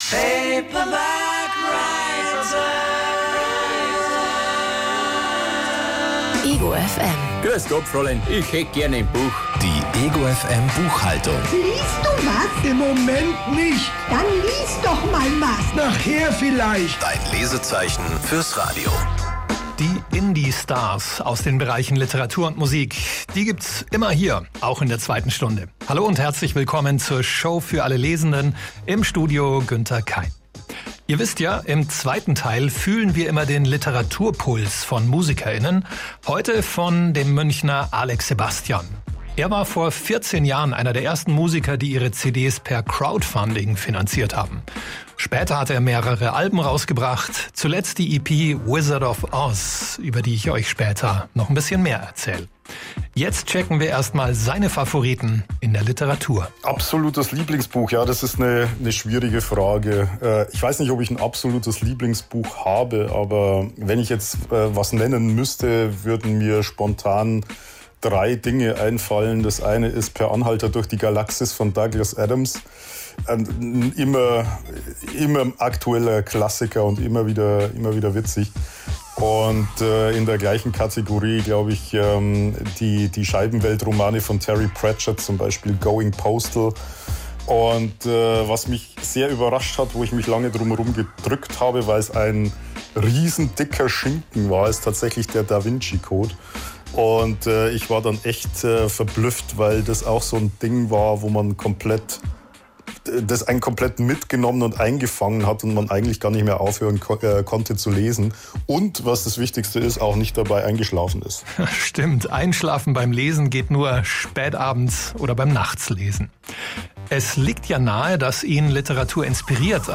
Zu Gast bei egoFM Buchhaltung